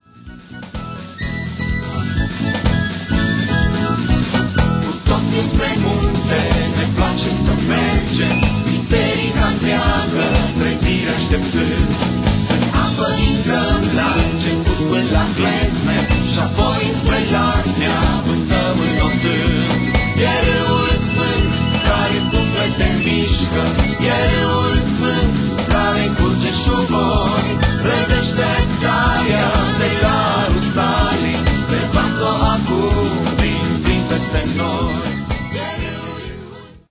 Un alt album de lauda si inchinare